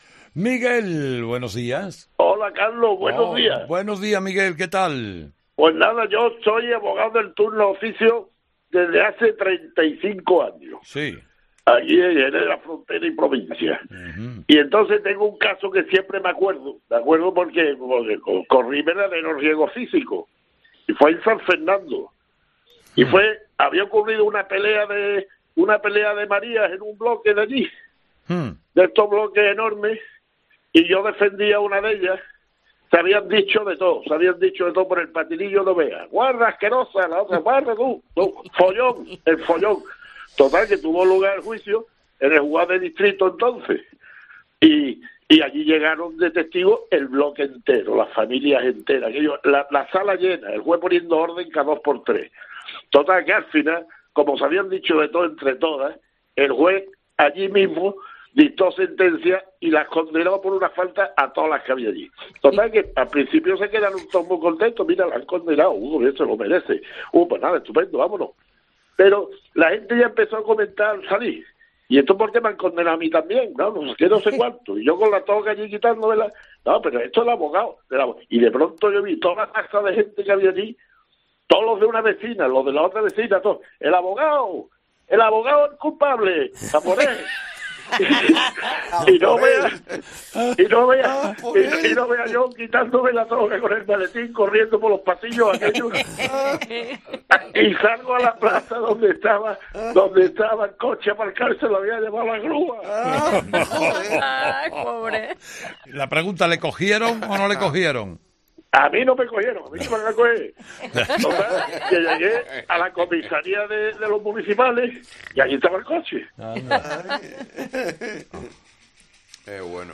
Este miércoles los oyentes han relatado historias desternillantes con el agua como protagonista
Los fósforos es el espacio en el que Carlos Herrera habla de tú a tú con los ciudadanos, en busca de experiencias de vida y anécdotas deliciosas, que confirman el buen humor y cercanía de los españoles.